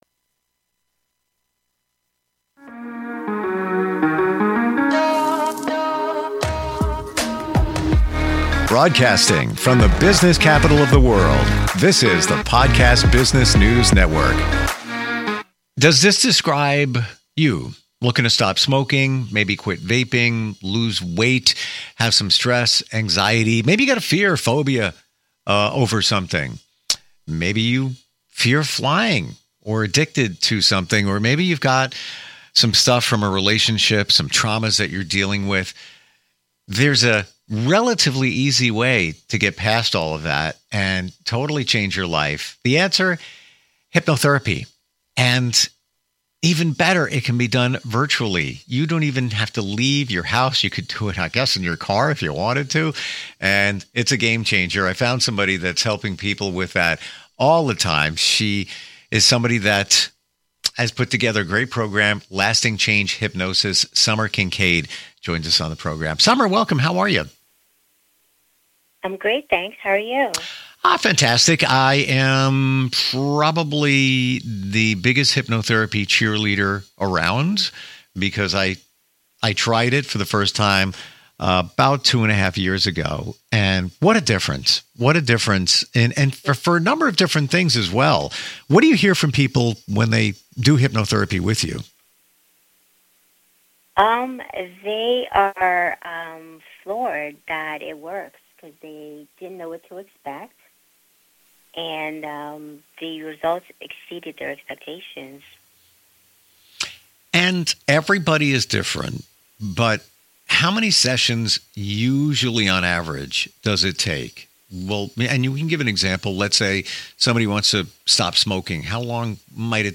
Listen to this informative podcast interview